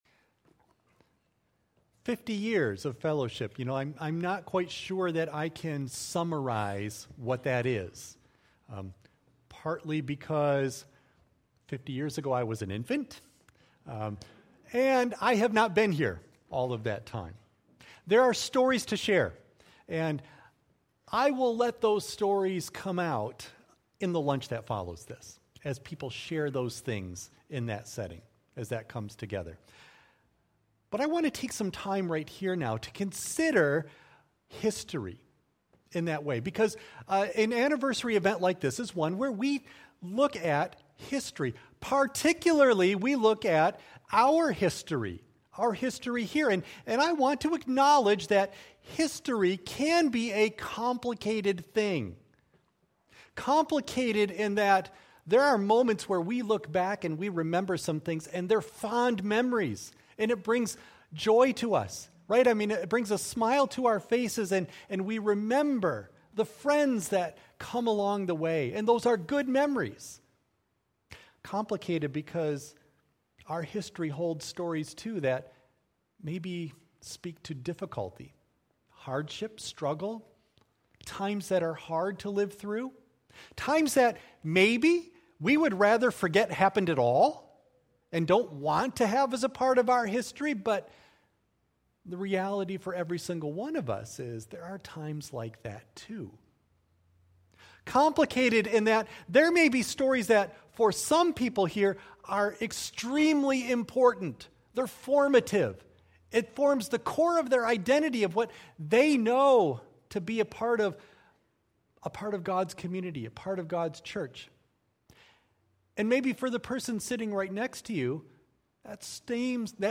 A 50th anniversary service which acknowledges God’s covenant faithfulness to Fellowship Church.
Audio of Message